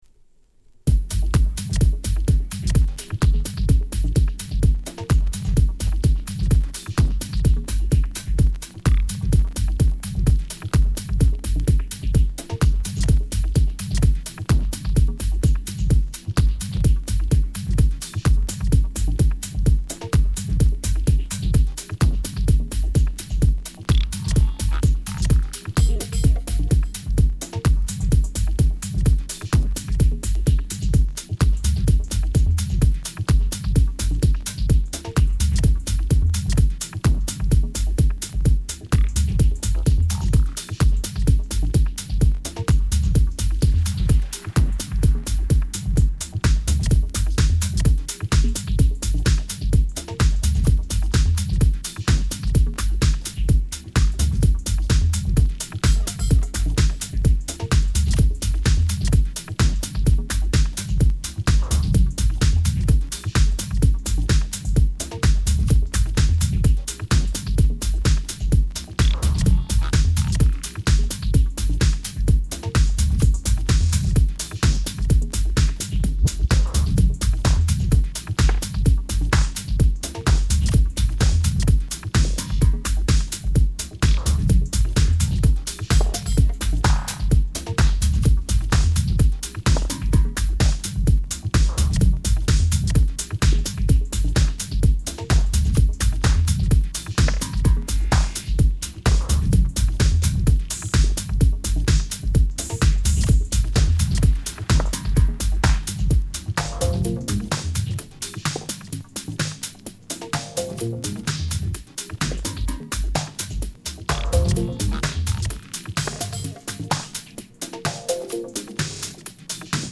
TOP > House / Techno > VARIOUS